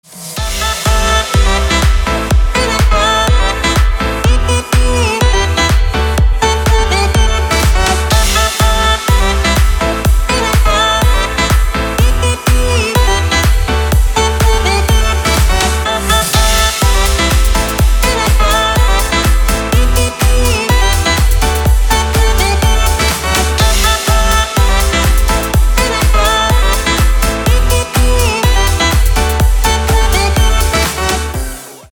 • Качество: 320, Stereo
громкие
Electronic
электронная музыка
без слов
клавишные
Dance Pop
house